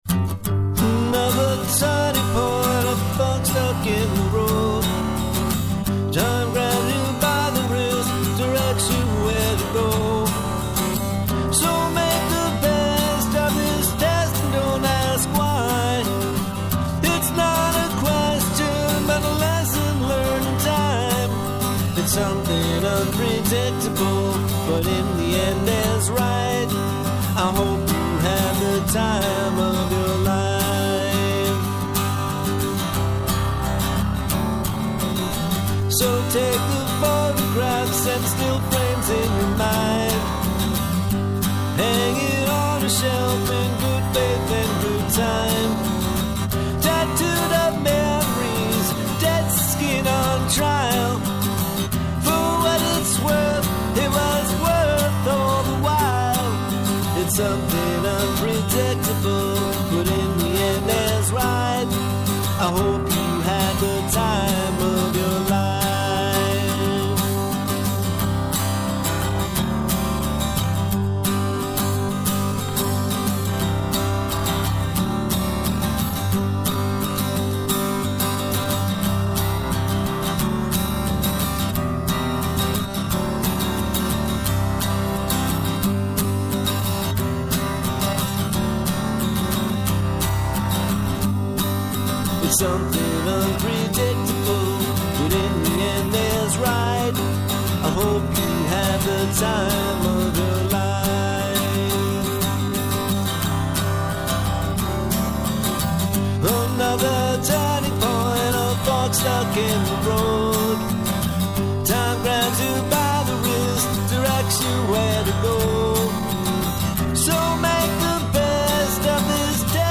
Vocals & Acoustic Guitar, Keys, Background Vocals
Lap Steel